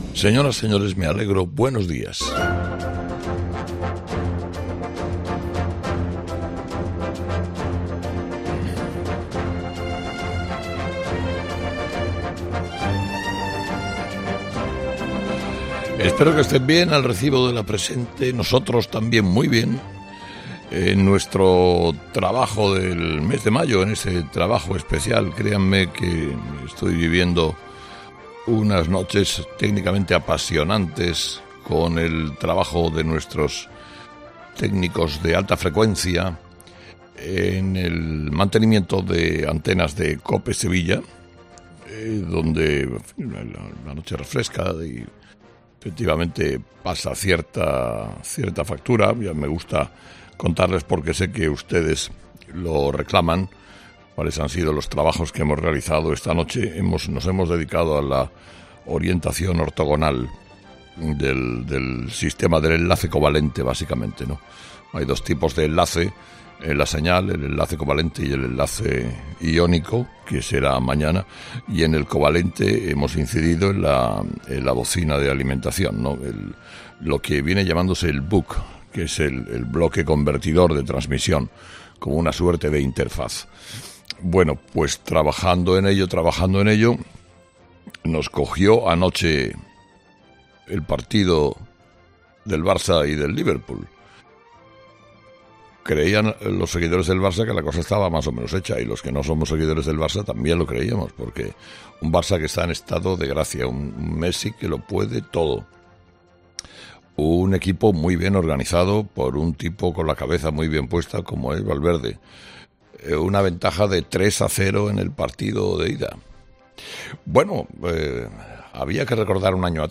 AUDIO: Herrera a las 8, miércoles 8 de mayo - Monólogo de las 8 de Herrera - COPE